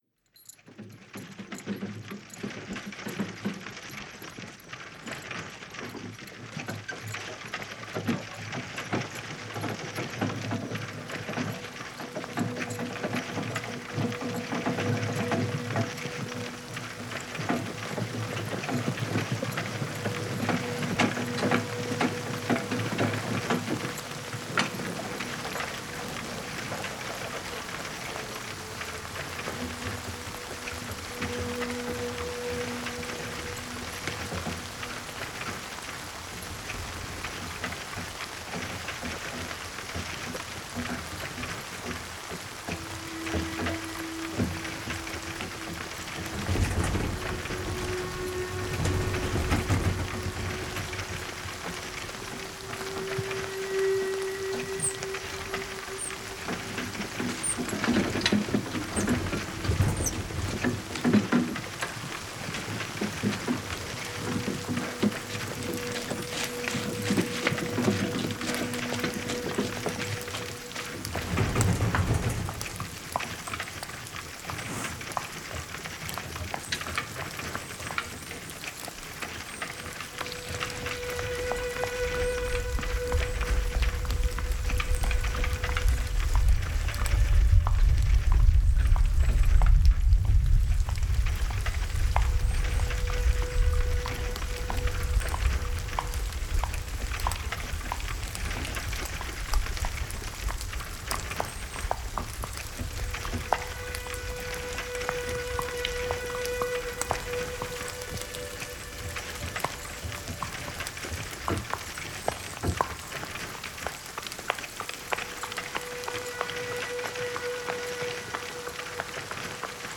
Погрузитесь в атмосферу прошлого с натуральными звуками повозки: скрип деревянных колес, цокот копыт лошадей, стук по неровной дороге.
Атмосферный звук кареты под дождем (для театра)